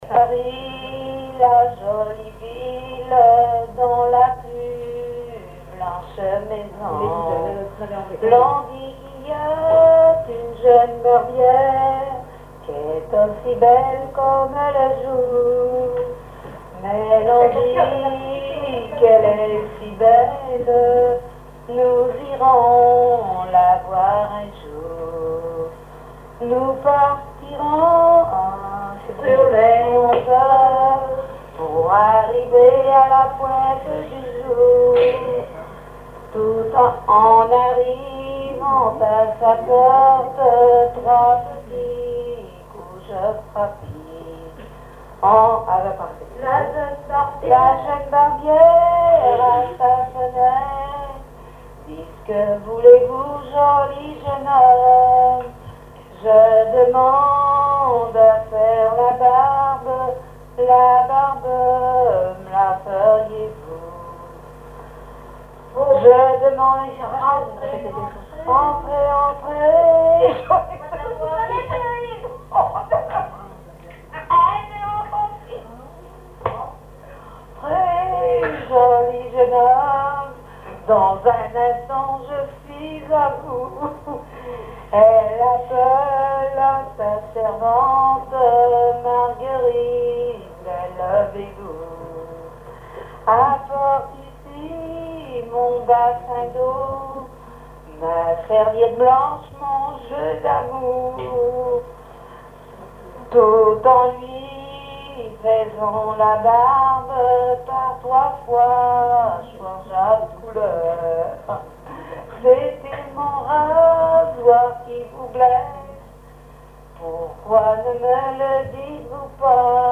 collecte en Vendée
Veillée de chansons
Pièce musicale inédite